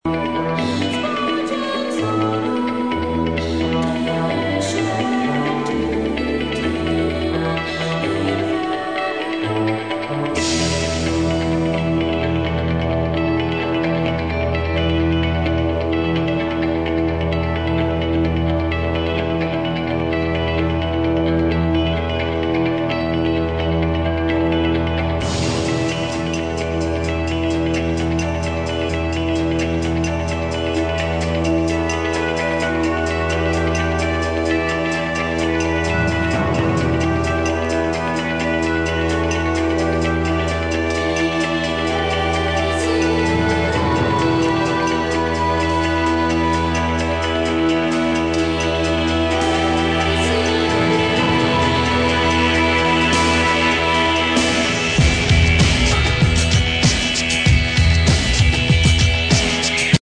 Rare & early electro/cosmic/progressive rock tracks.